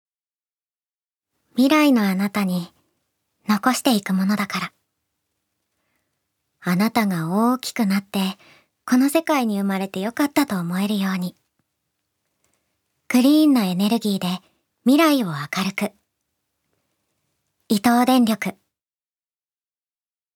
ナレーション２